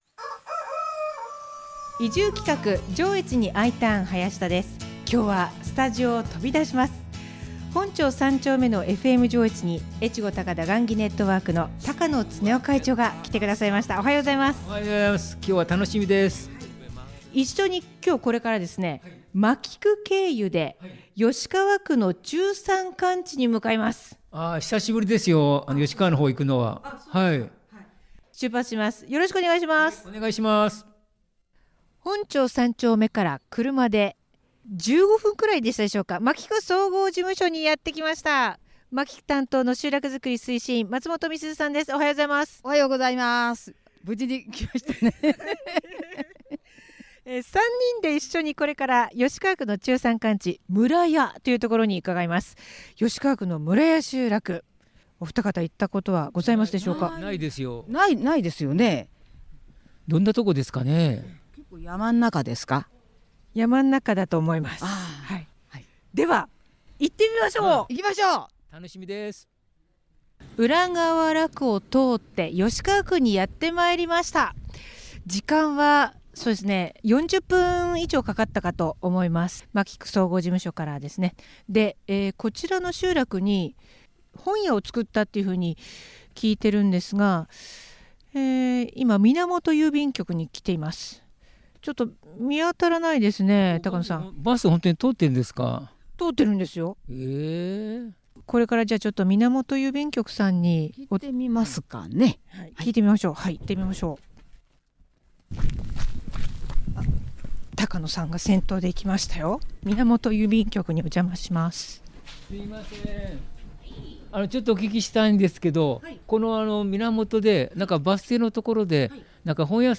4/17と4/24の放送は、スタジオを飛び出して このほど吉川区の村屋集落に出来た「村の本屋」さんをご紹介。